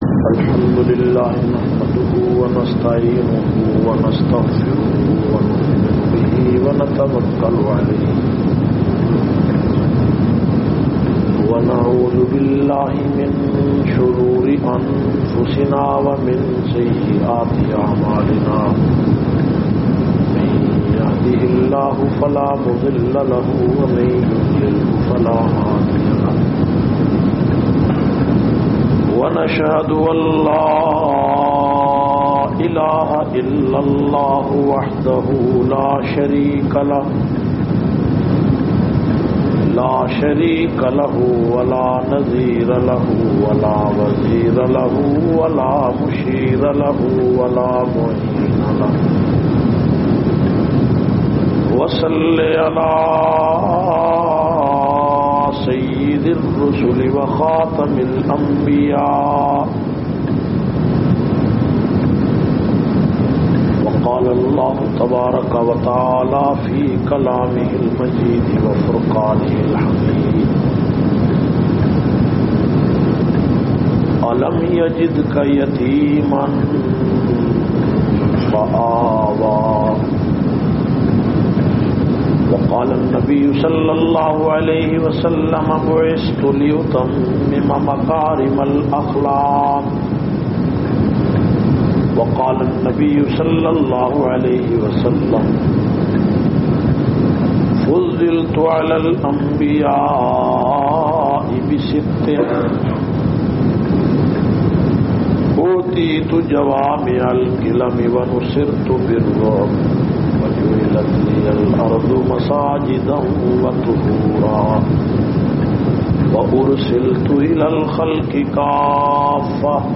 406- Yateem e Makkah (Wiladat e Mustafa Conference Jamia Masjid Edinburgh England).mp3